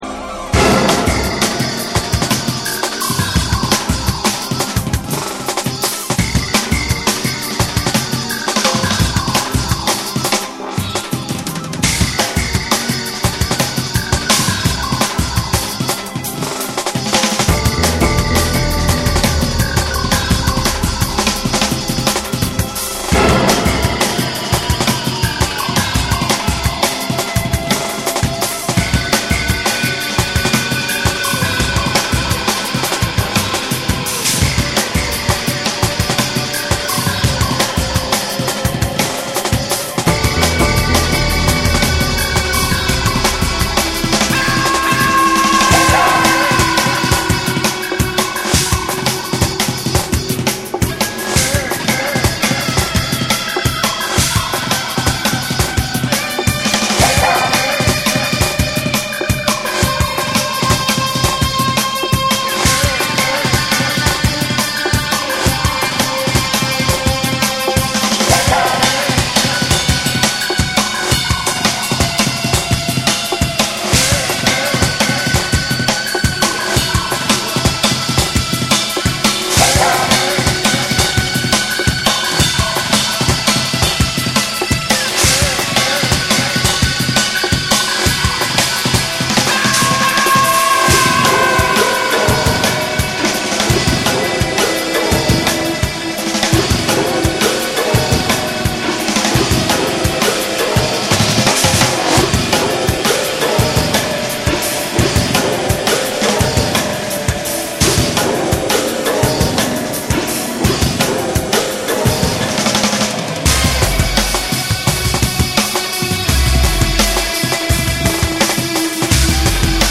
アグレッシヴに乱れ打つドラムンベース
BREAKBEATS / JUNGLE & DRUM'N BASS